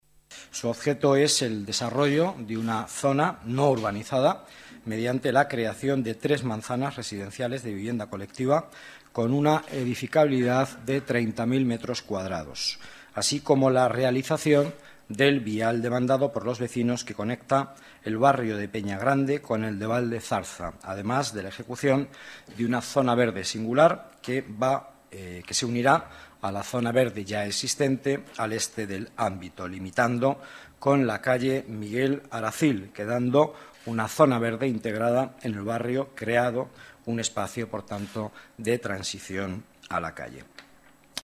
Nueva ventana:Declaraciones del vicealcalde, Miguel Ángel Villanueva